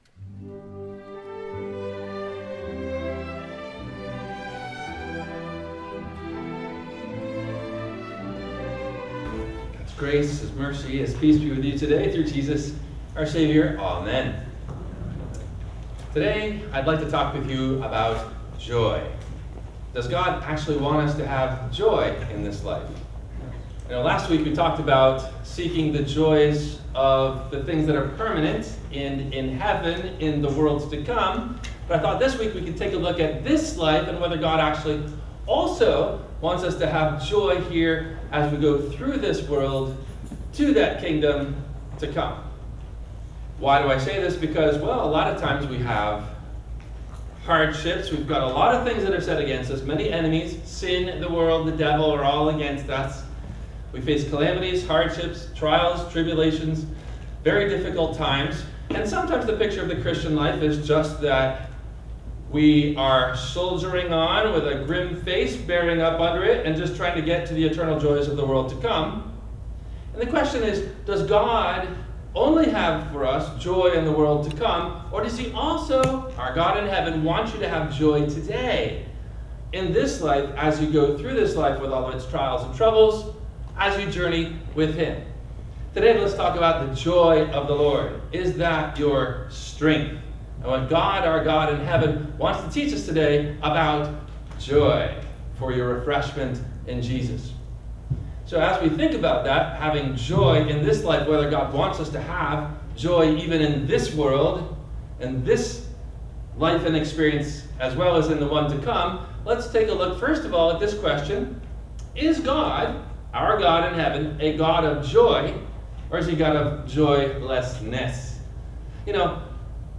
Does God Want You To Have … Joy? – WMIE Radio Sermon – September 08 2025